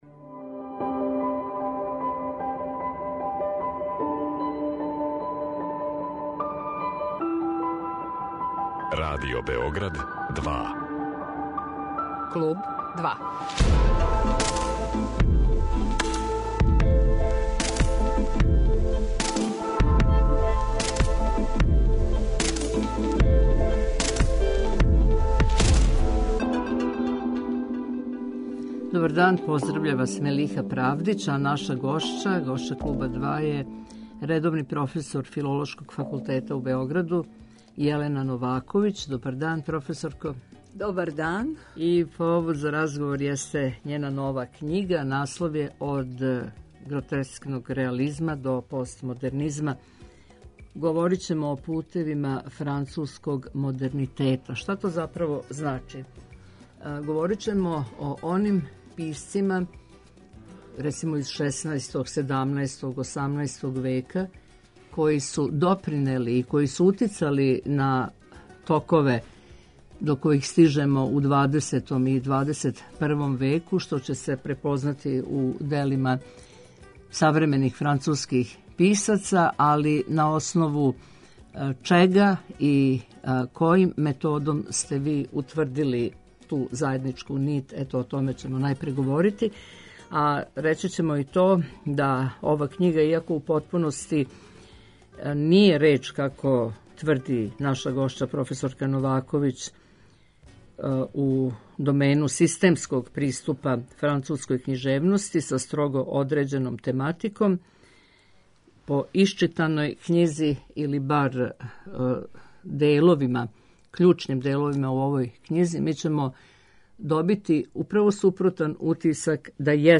О томе, али и о вишедеценијским универзитетским прегнућима наше гошће у афирмацији француске књижевности и француског језика такође разговарамо.